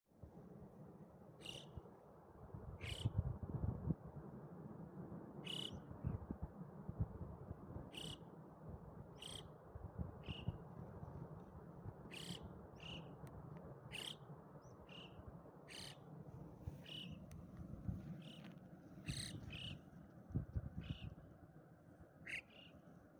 Wobei die Rufe variieren können
Alpendohlen-am-Grossglockner-4.mp3